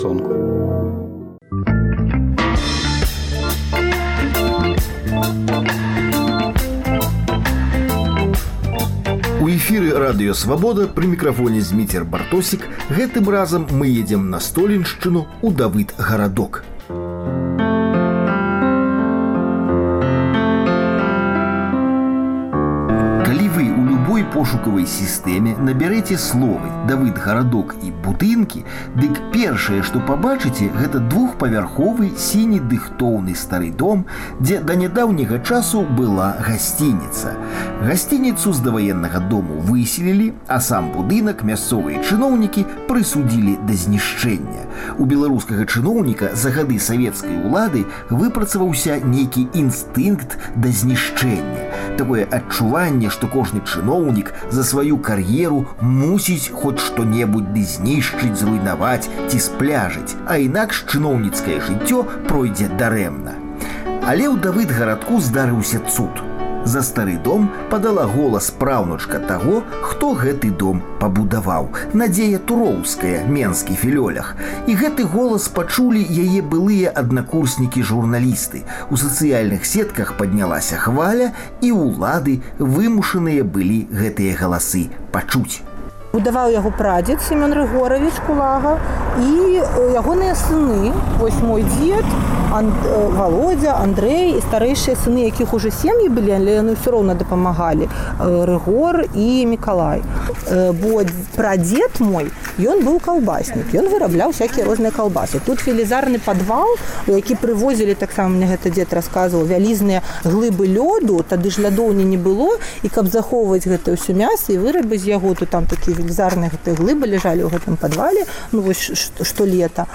У «Начной чытанцы» — 100 сучасных аўтараў чыталі свае творы на Свабодзе.